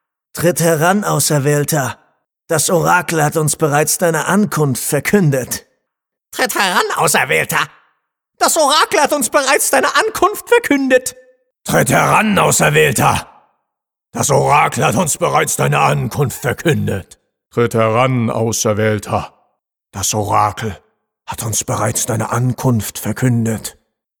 Vielseitig einsetzbare und wandlungsfähige Stimme – von wohlklingend bassig über jugendlich dynamisch bis hin zu Trickstimmen.
Sprechprobe: eLearning (Muttersprache):